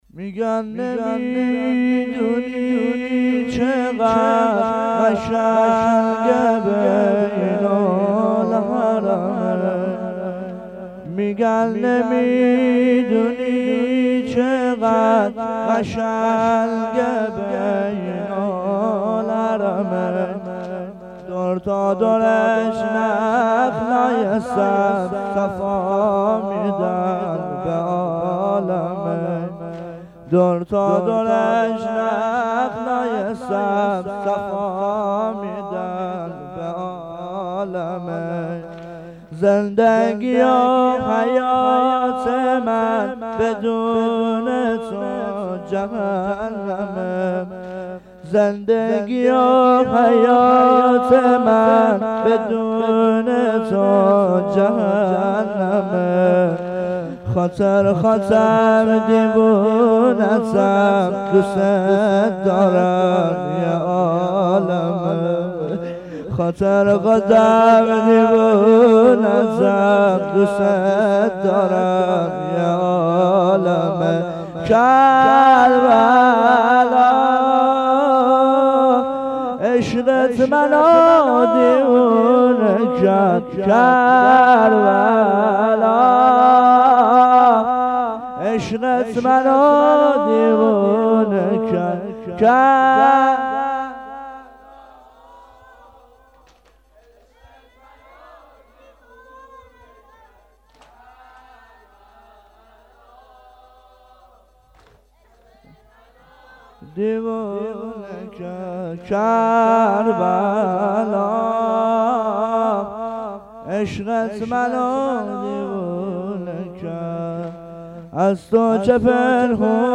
مداحی واحد